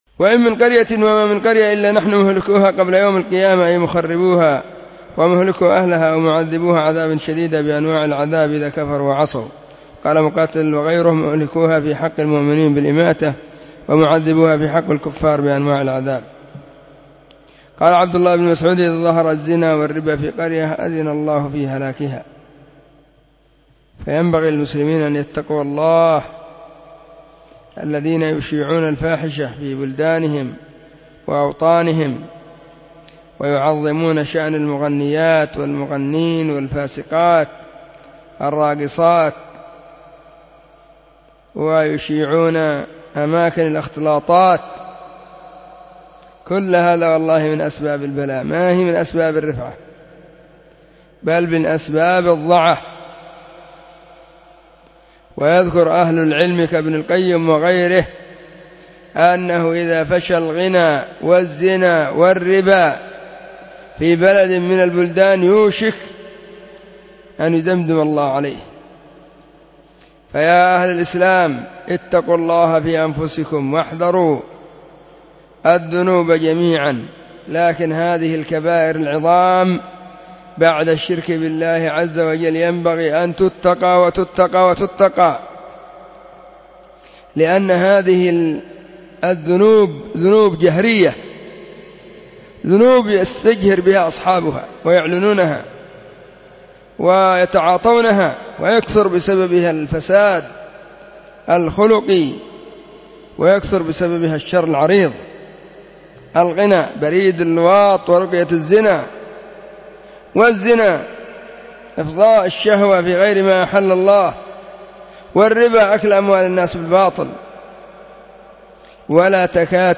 📢 مسجد الصحابة بالغيضة, المهرة، اليمن حرسها الله.
نصيحة-من-درس-تفسير-البغوي.mp3